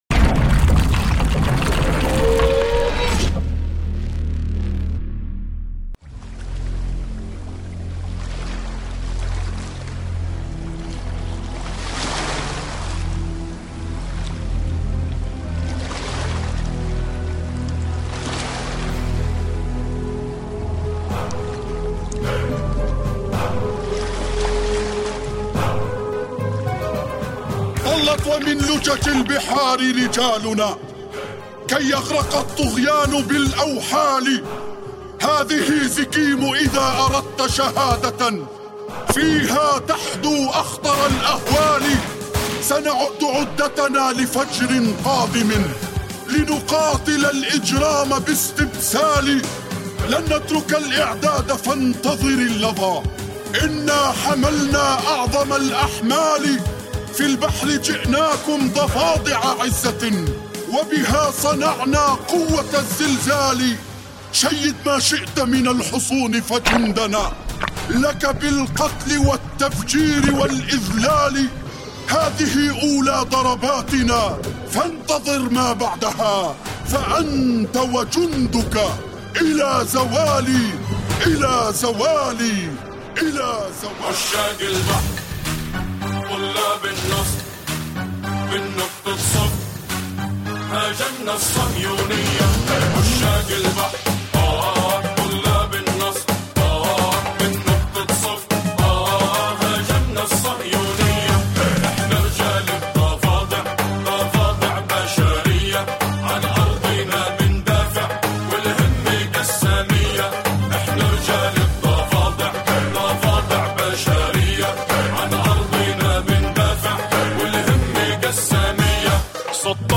انشودة
اناشيد غزاوية قسامية